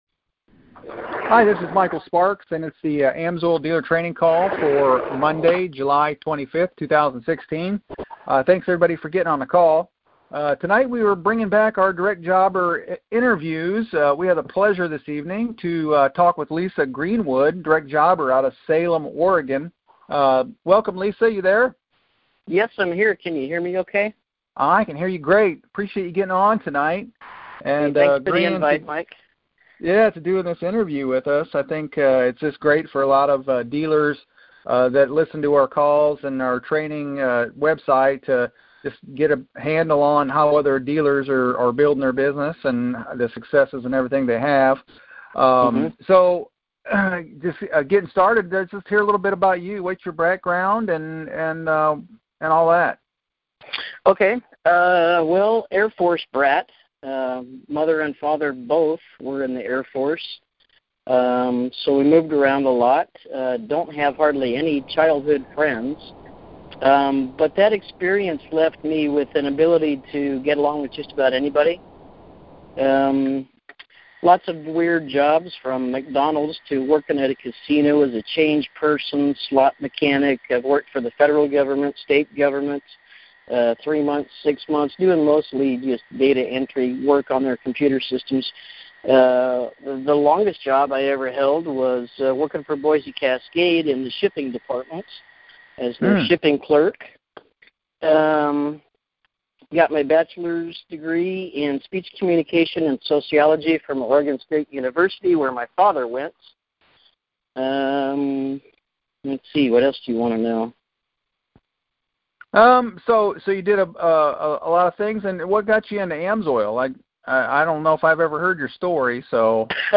AMSOIL Best Dealer Training Team Training Call